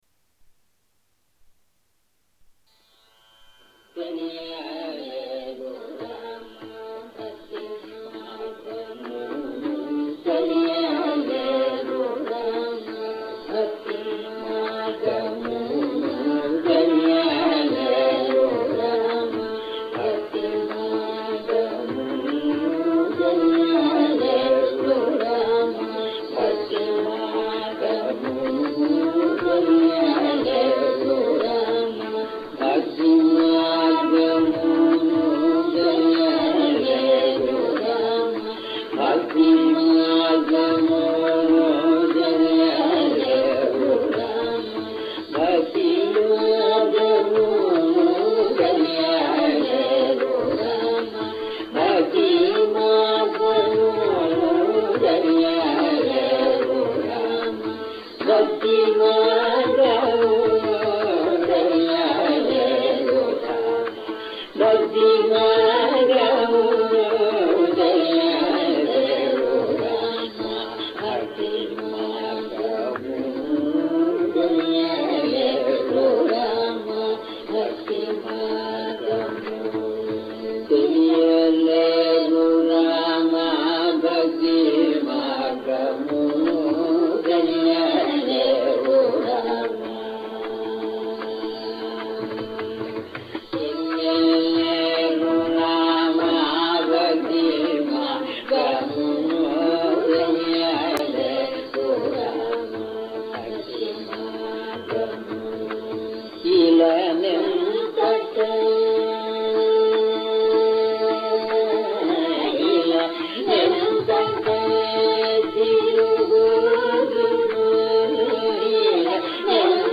Learning in Carnatic music takes place at two levels.
a duet rendering
Ragam Dhenuka